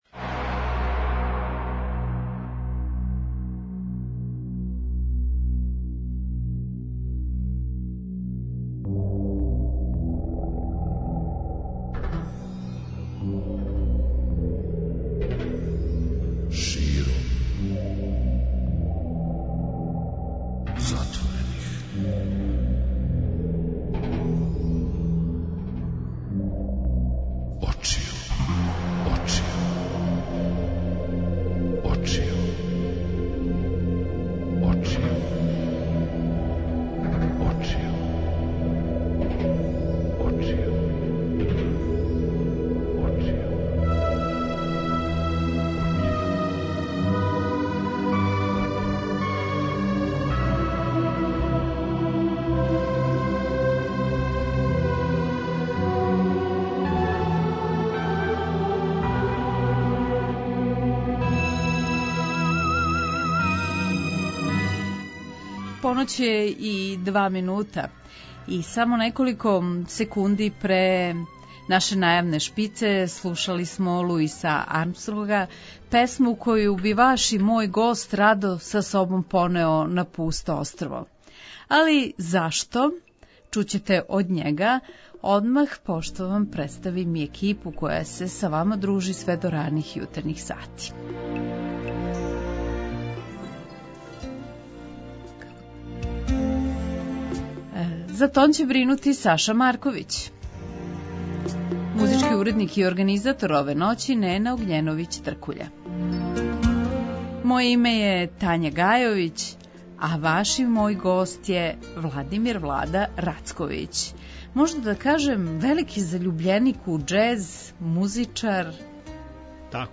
Пре једног века чланови састава Original Dixieland Jass Band исписали су историју снимивши прву џез плочу.